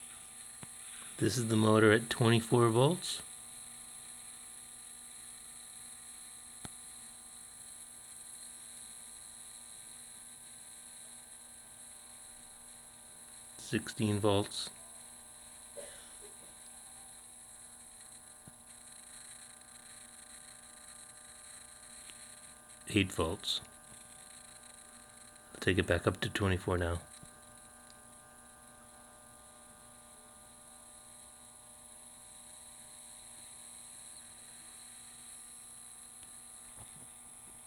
In either case, the duty cycle gets longer, but the jitter still remains, and therefore there is some undesired voltage noise applied to the motor.  To give you and idea of the sound, I've attached an audio file, recorded with my phone microphone next to the motor.
Motor-Noise.wav